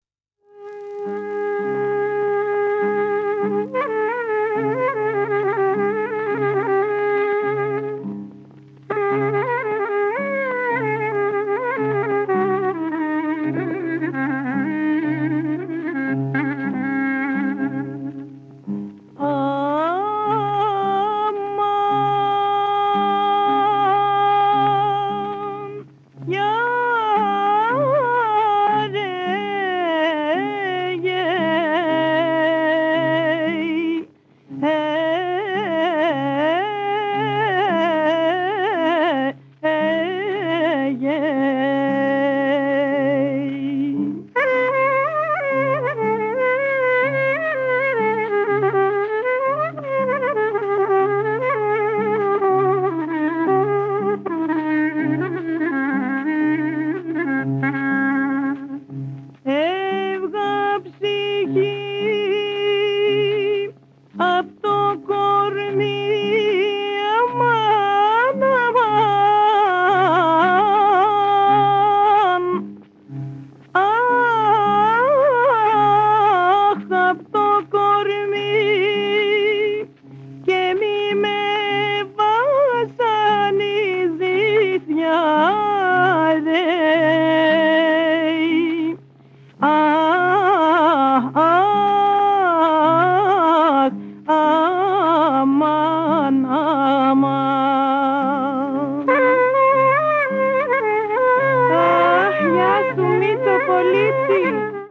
コブシの効いた歌が描く、ディープな情念的な世界がとにかく最高！
トルコ音楽とも繋がる地中海的な味わいも◎！
試聴1